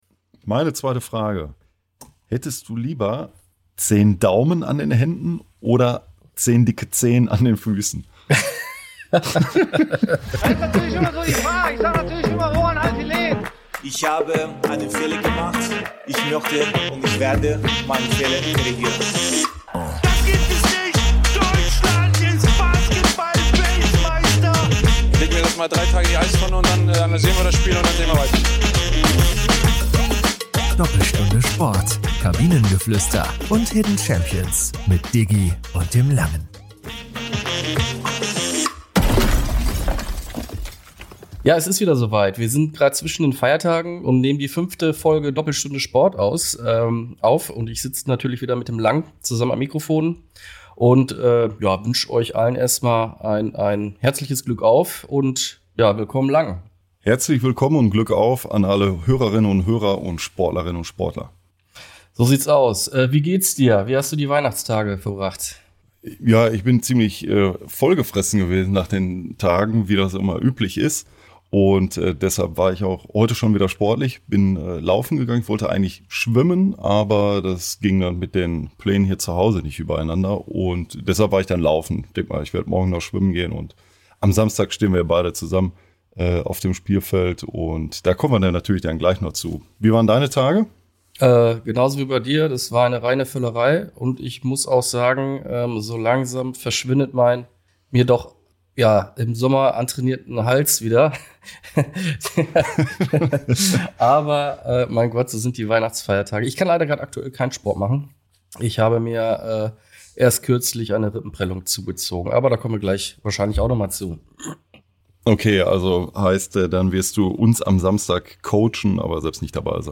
entschuldigt deswegen auch die etwas schlechtere Soundqualität in dieser Folge